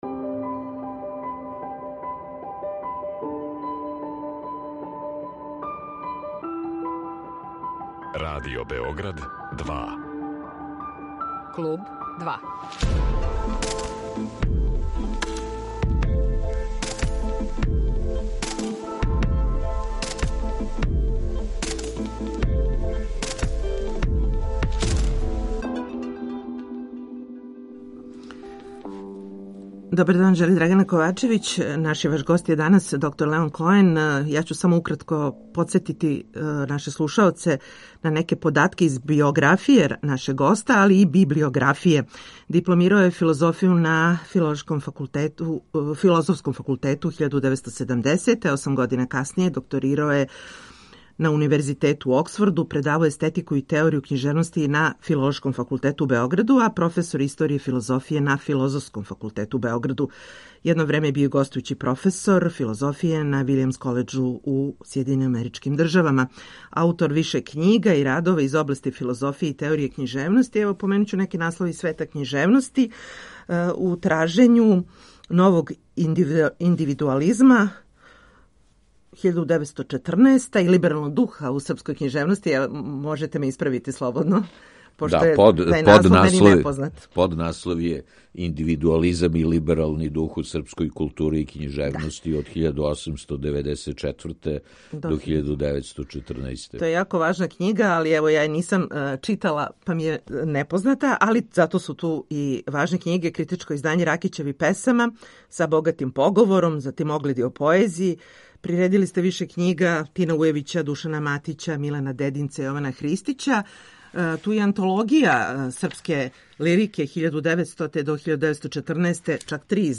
То је само повод да са нашим данашњим гостом разговарамо о писцима и књигама које су обележиле његов живот и рад.